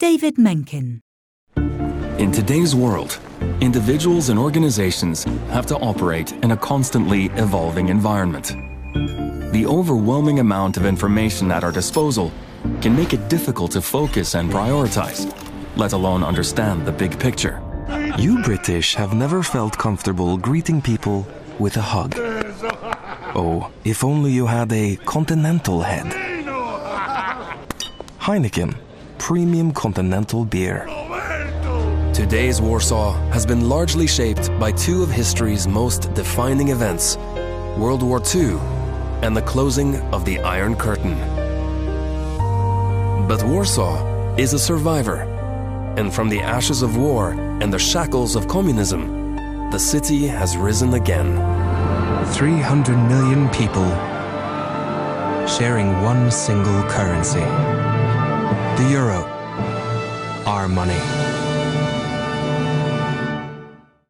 Norwegian voiceover artist: contact his agent direct for male Norwegian voice overs, recordings and session work.